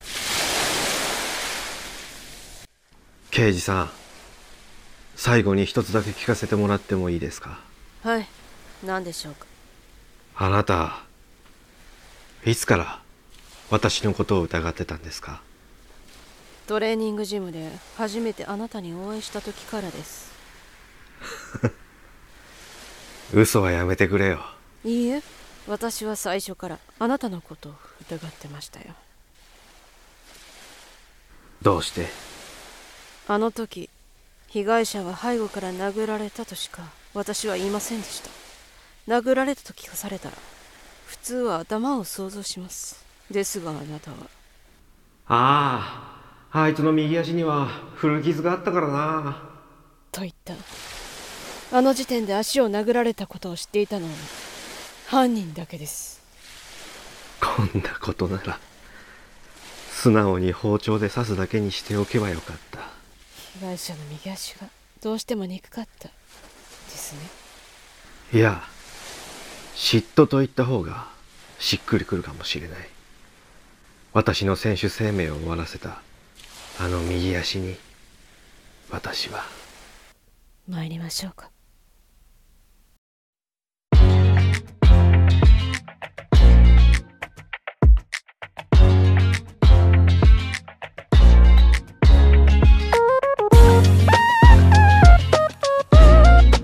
【声劇】刑事ドラマのラスト (リメイク）